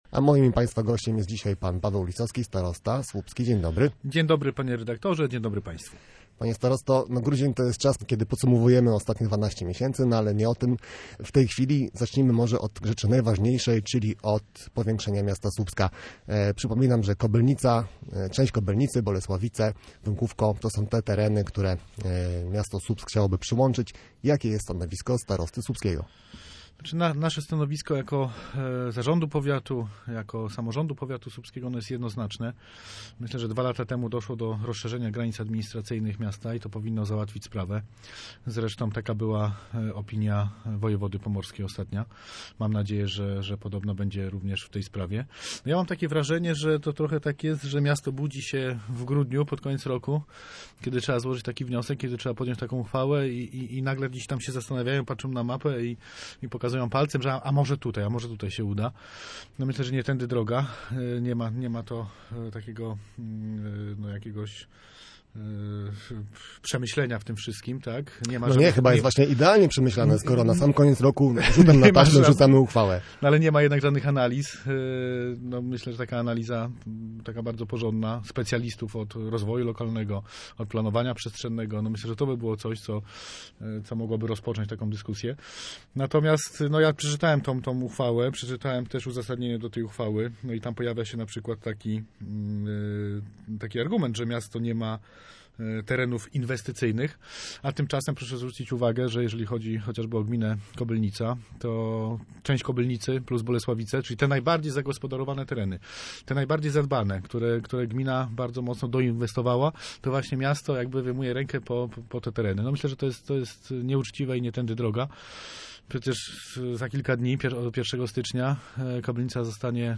Starosta słupski Paweł Lisowski był gościem Studia Słupsk Radia Gdańsk
O tym, jaki był ten rok i czego spodziewać się w kolejnym rozmawiamy z Paweł Lisowskim, starostą słupskim.